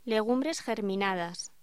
Locución: Legumbres germinadas
voz